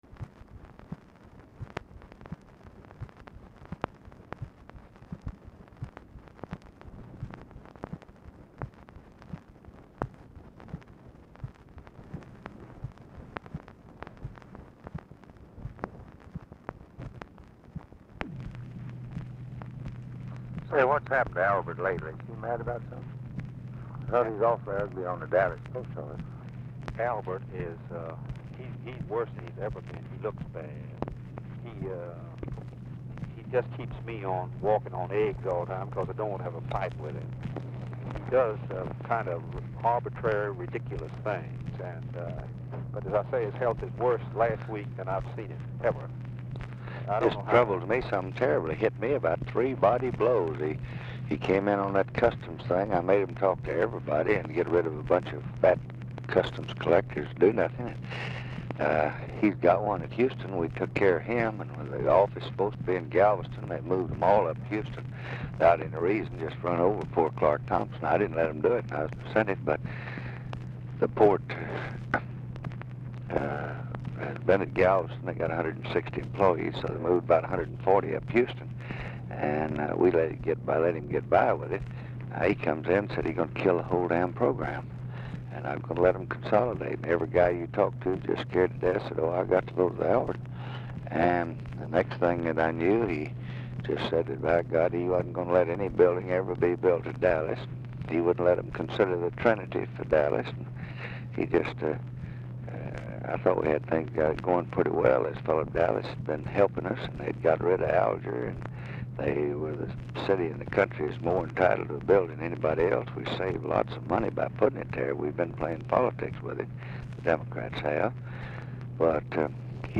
Format Dictation belt
Location Of Speaker 1 Mansion, White House, Washington, DC
Specific Item Type Telephone conversation Subject Congressional Relations Defense Federal Budget Legislation Procurement And Disposal Taxes Texas Politics Vietnam Vietnam Criticism